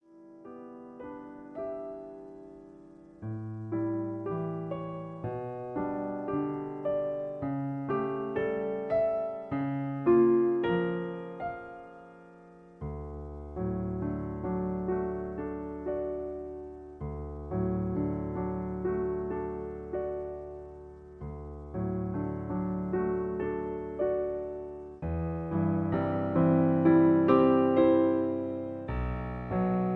Piano Accompaniment